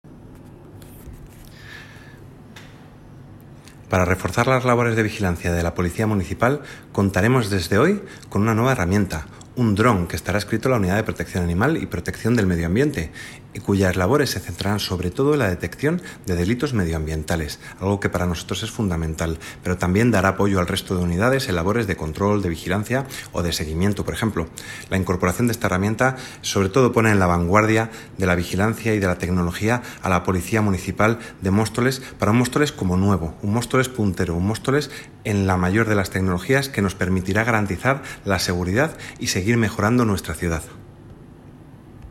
Declaraciones de Alex Martin Portavoz de Seguridad, Convivencia, Cultura y Transición Ecológica - Presentación Dron